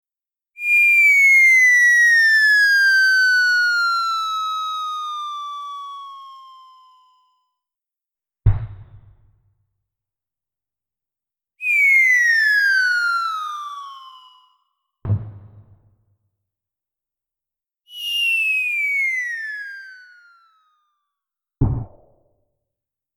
Download Free Falling Sound Effects
Falling